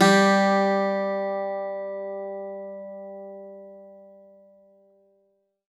52-str03-bouz-g2.wav